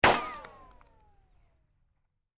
Pistol Fire with Ricochet; Single, Sharp, High Whine Sounding Ricochet. Close Up Perspective. Gunshots.